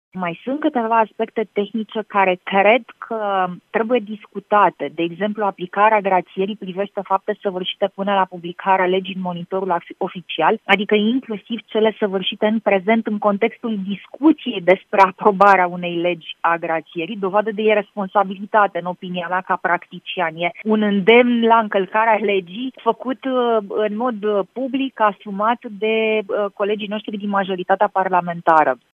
Senatoarea Alina Gorghiu, membră a Comisiei Juridice din Senat, a declarat la Europa FM că ”grațierea a devenit o obsesie” pentru PSD.